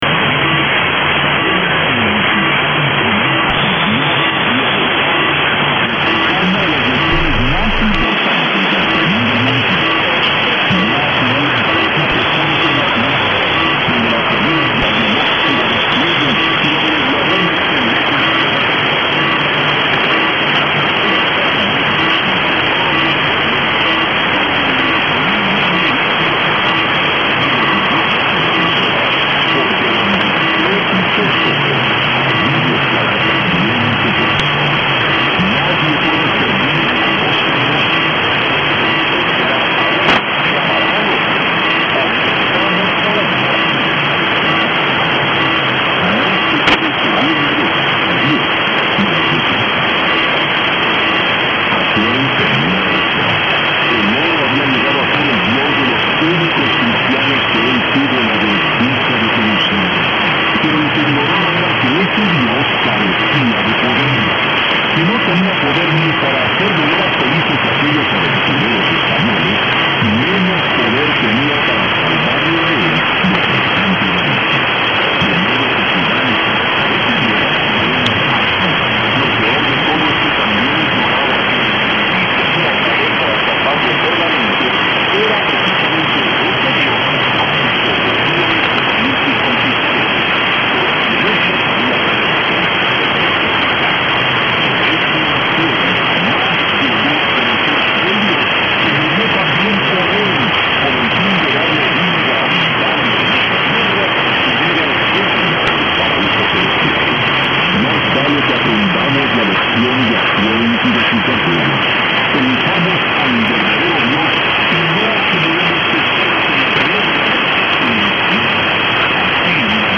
Spanish and religious, although I could not make out much due to the sideband-ish sound. Like someone else, I thought it to be not a pirate produced program but rather something "professional".